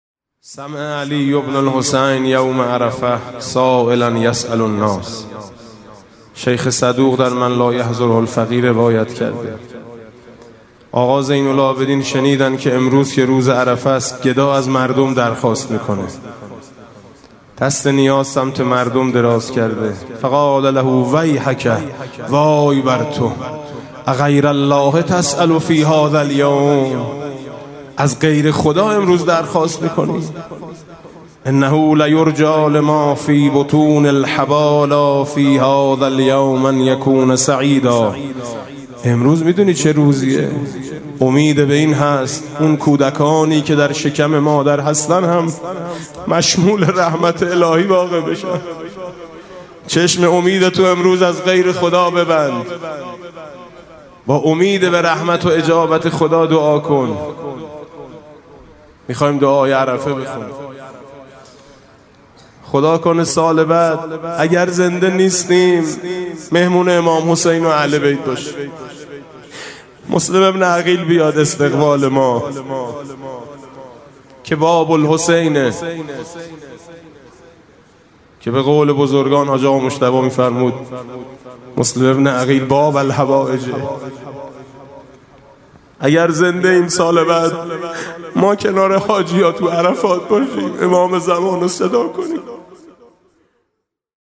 [آستان مقدس امامزاده قاضي الصابر (ع)]
مناسبت: قرائت دعای عرفه